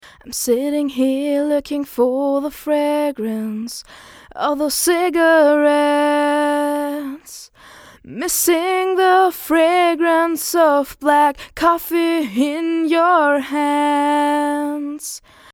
mc-520 - voc - pur.mp3